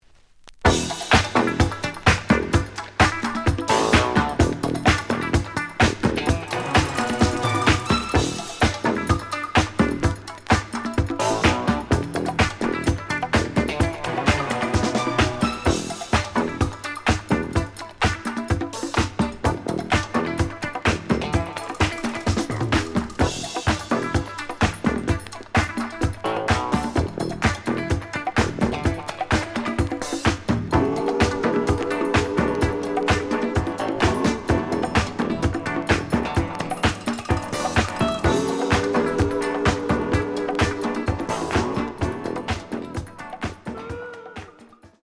The audio sample is recorded from the actual item.
●Genre: Disco
Slight damage on both side labels. Plays good.)